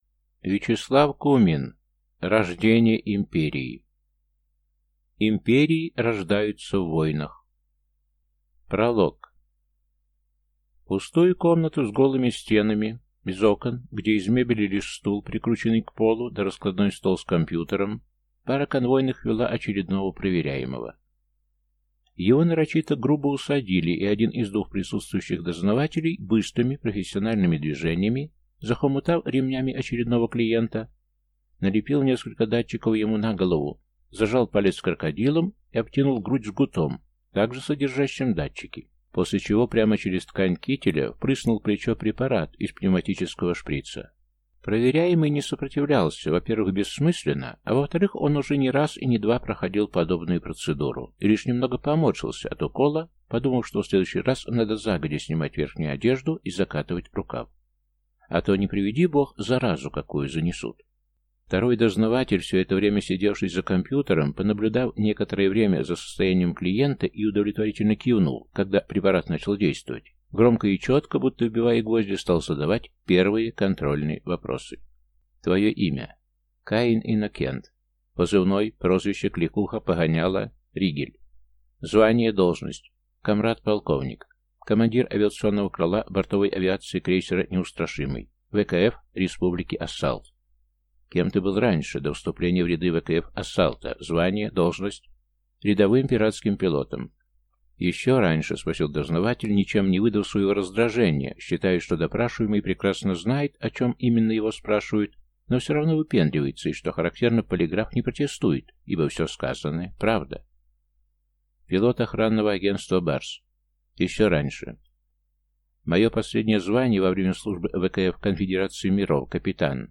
Аудиокнига Рождение империи | Библиотека аудиокниг